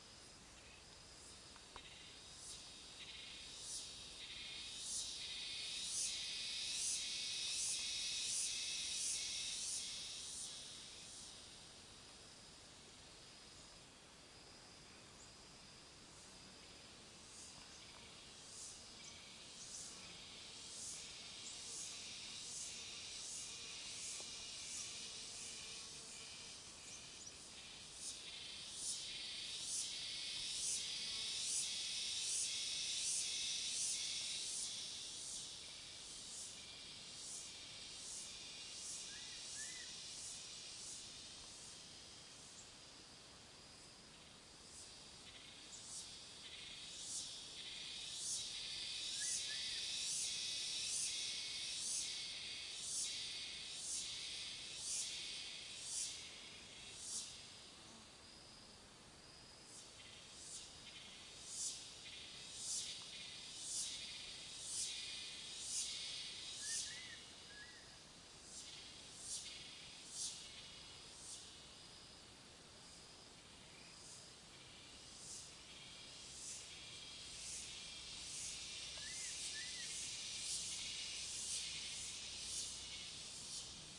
BR 016 VN 蝉
Tag: 氛围 越南 昆虫 性质 现场录音